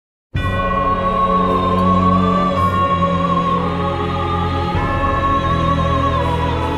dark-souls-grey-wolf.mp3